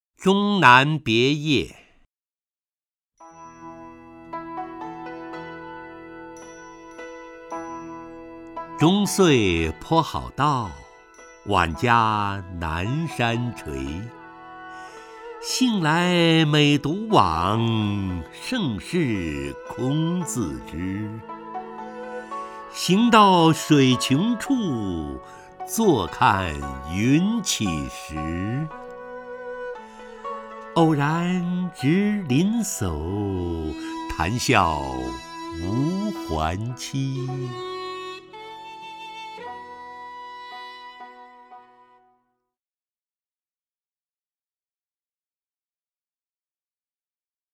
陈醇朗诵：《终南别业》(（唐）王维) （唐）王维 名家朗诵欣赏陈醇 语文PLUS